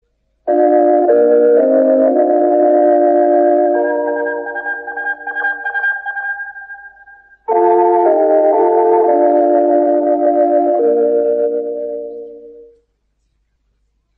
Звуковая заставка отечественного радио на АМ волнах